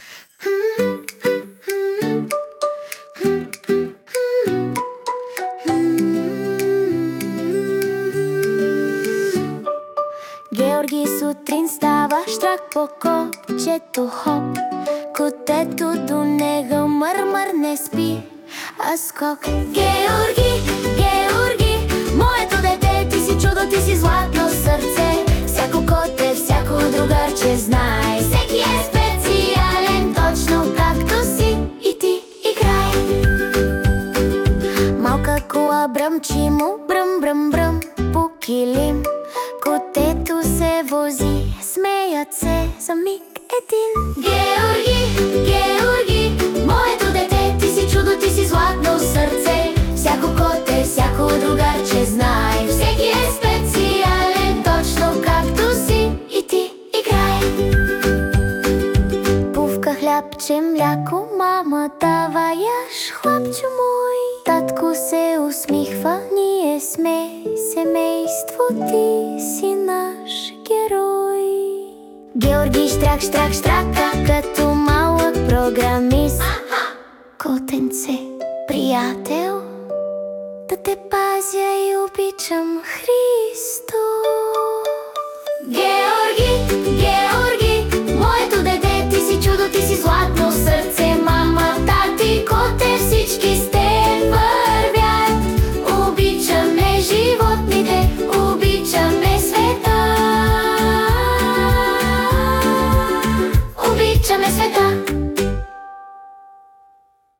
Персонализирана песен за дете